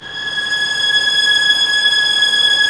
Index of /90_sSampleCDs/Roland LCDP13 String Sections/STR_Violins III/STR_Vls6 mf%f St